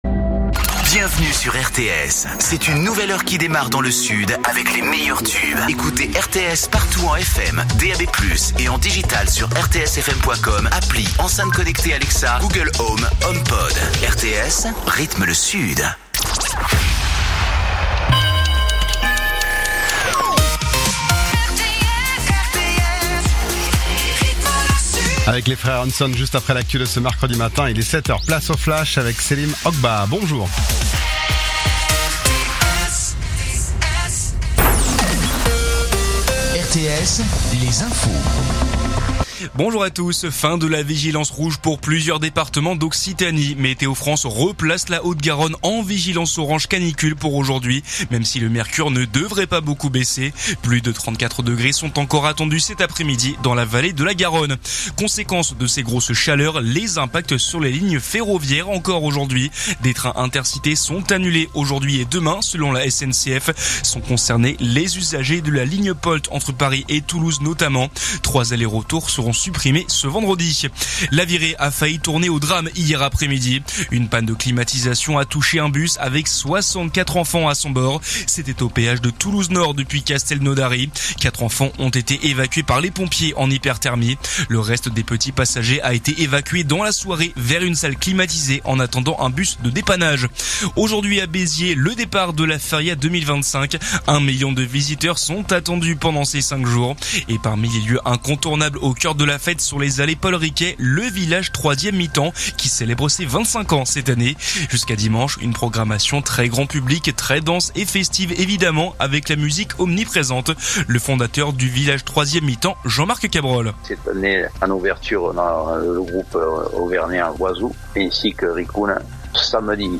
Écoutez les dernières actus de Perpignan en 3 min : faits divers, économie, politique, sport, météo. 7h,7h30,8h,8h30,9h,17h,18h,19h.